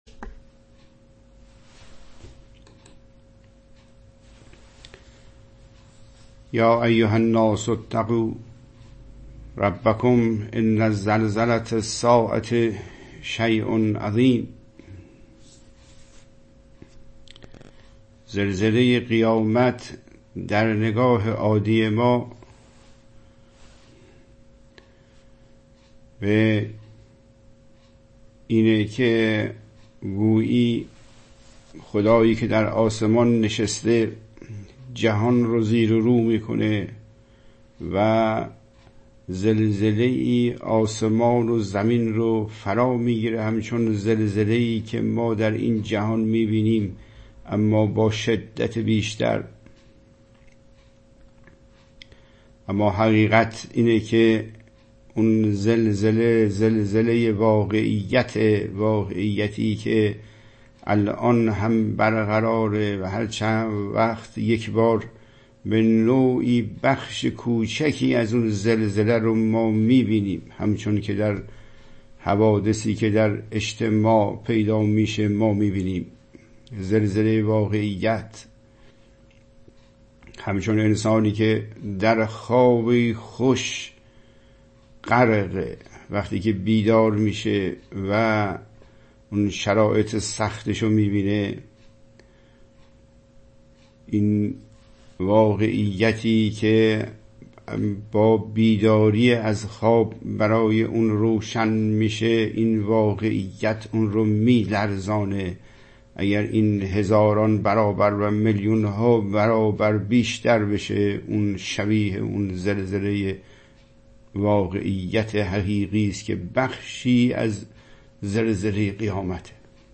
طبق روال قبلی برنامه انلاین آن با پخش آیاتی از قرآن مجید آغاز شده و حاضرین با مخاطبه و تکلیم با متن به توجه به نفس می‌پردازند.
در جلسه ارائه شفاهی صورت خواهد گرفت ولی سوال مکتوب یا شفاهی طرح نخواهد شد.